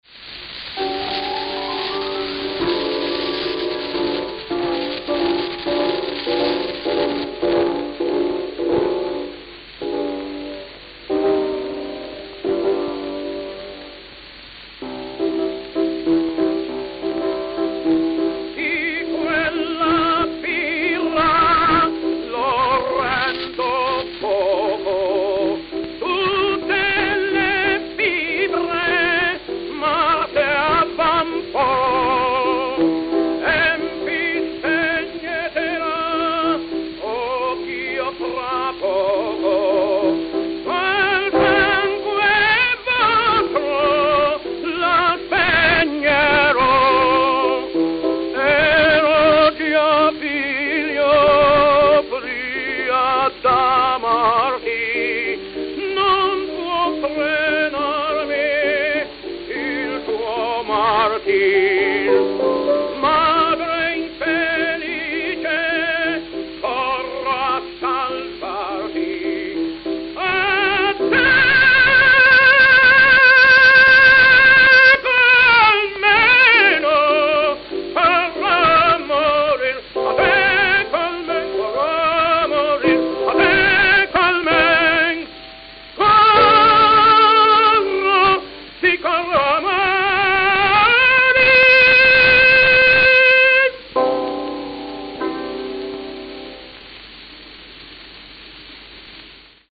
Victor Red Seal 78 RPM Records
Francesco Tamagno
Ospedaletti, Italy
Note: Very worn. Talking after selection is over.